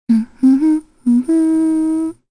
Kirze-Vox_Hum_kr_b.wav